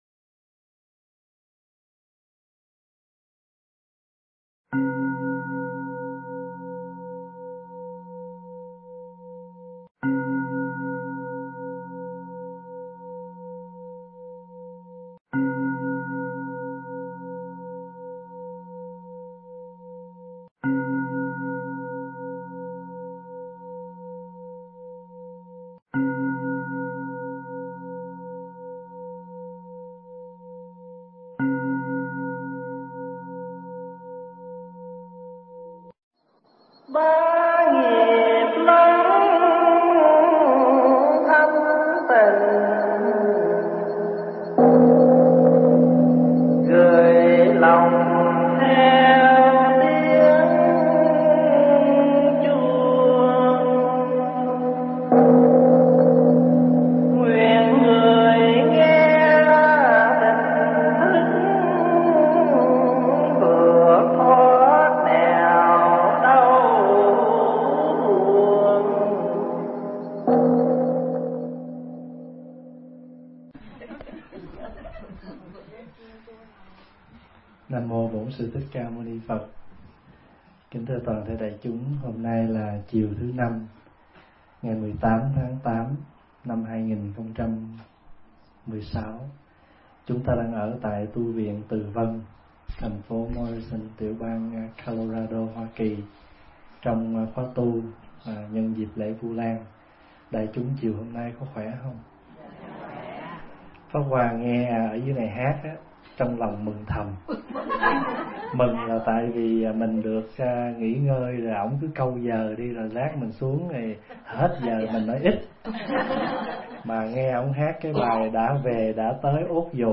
Tu Viện Từ Vân